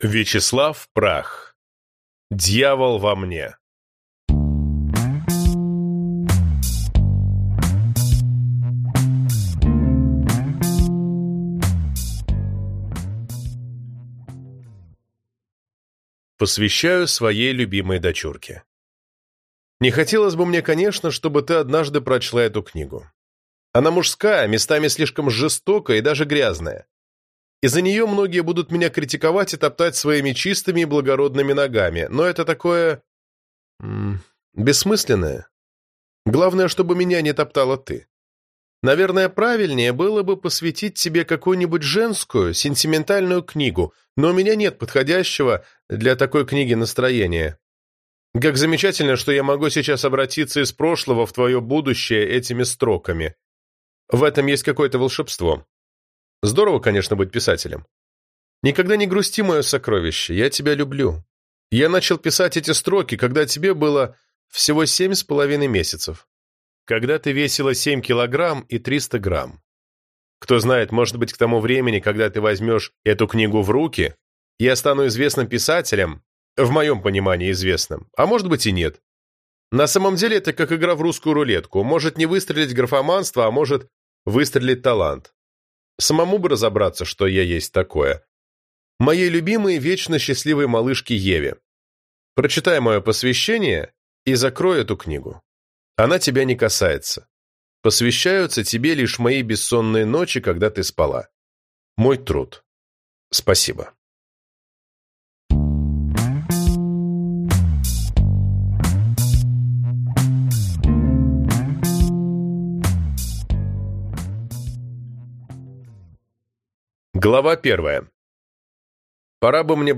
Аудиокнига Дьявол во мне - купить, скачать и слушать онлайн | КнигоПоиск